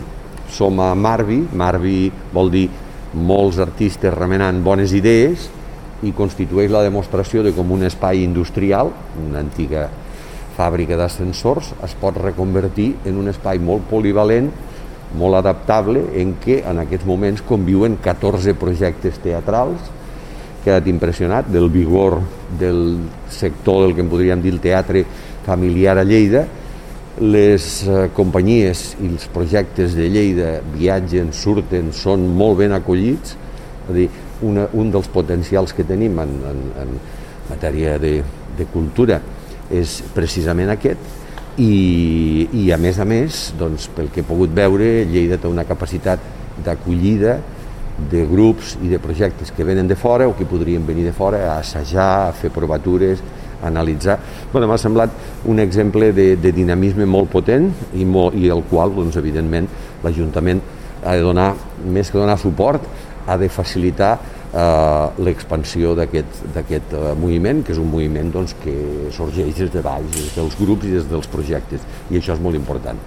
Tall de veu de Miquel Pueyo
tall-de-veu-del-paer-en-cap-miquel-pueyo-sobre-la-visita-a-marbi